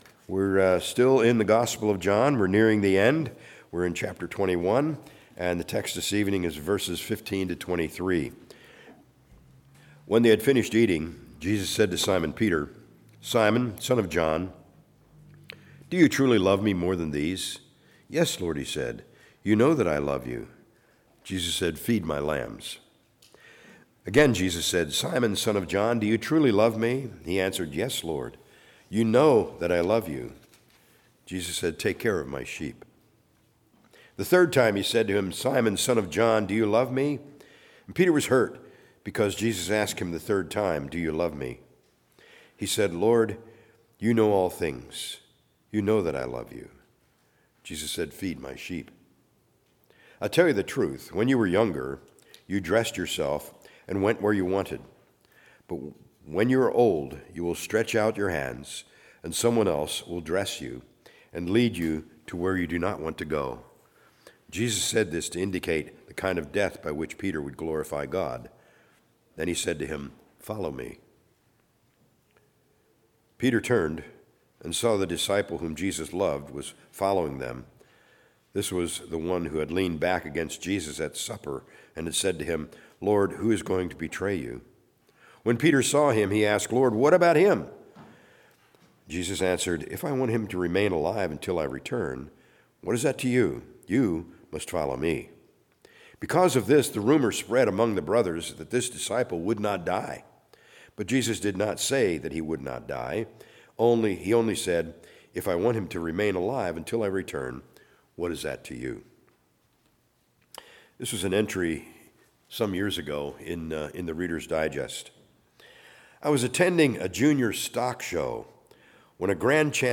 A message from the series "John."